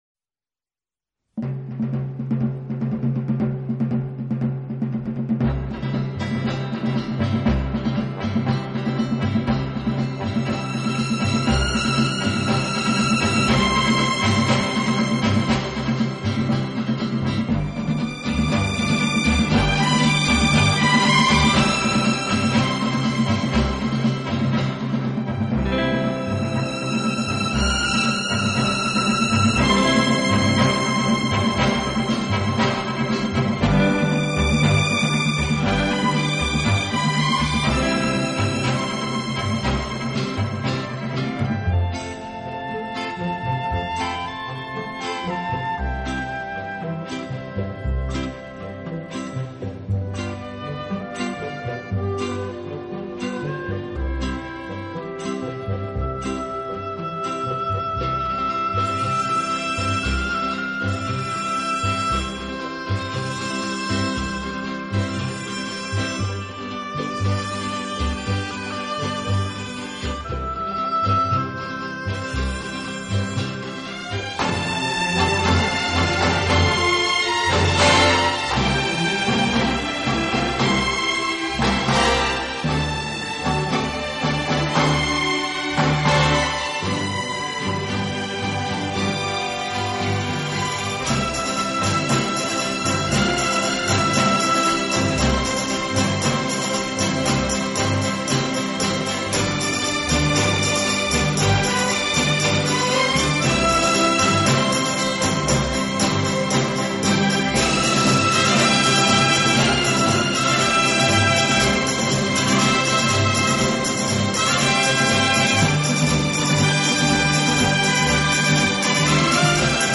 【顶级轻音乐】
大量弦乐器织成的“弦瀑”
一波一浪的小提琴，静若花落水面，动如飞瀑流泻。
这个乐团的演奏风格流畅舒展，
旋律优美、动听，音响华丽丰满。